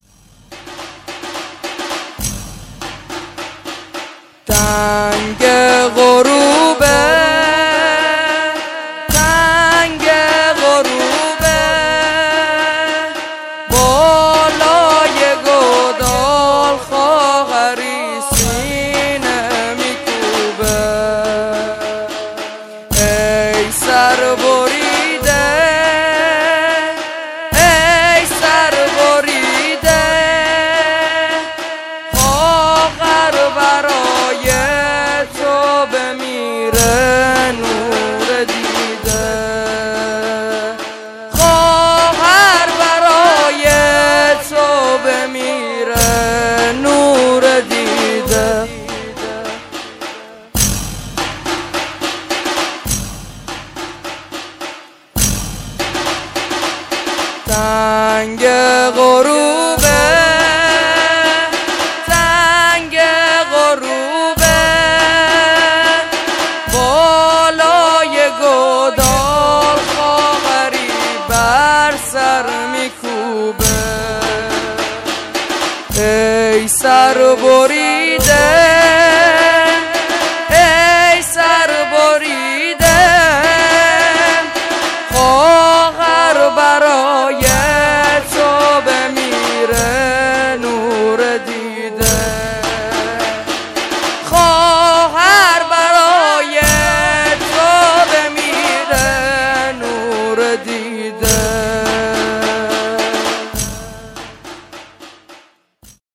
هیئت رهروان شهدا شهرستان دزفول
زنجیرزنی (تنگه غروبه) شب کفن و دفن محرم الحرام سال ۱۴۴۲